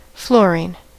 Ääntäminen
Ääntäminen US Tuntematon aksentti: IPA : /ˈflʊəriːn/, /ˈflɔːriːn/ IPA : /ˈflʊəriːn/ IPA : /ˈflʊərɪn/ IPA : /ˈflɔəriːn/ Haettu sana löytyi näillä lähdekielillä: englanti Käännös Ääninäyte Substantiivit 1.